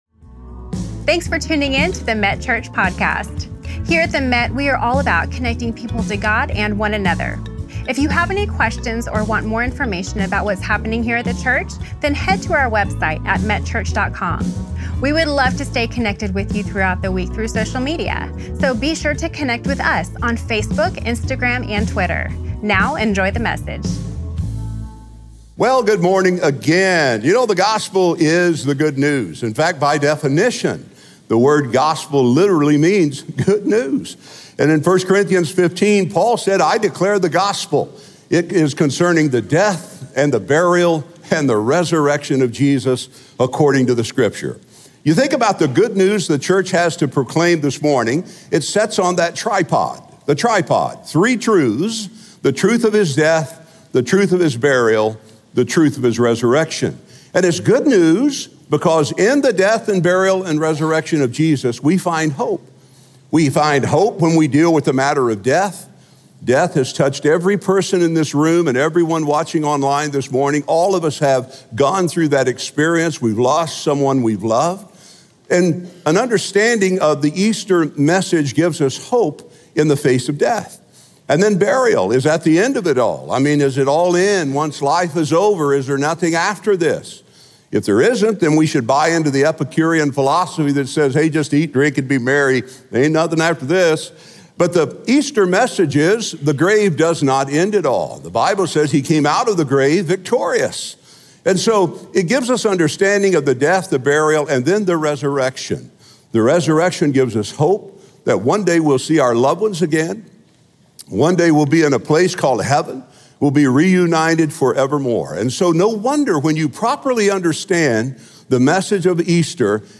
Comeback, on Easter Sunday.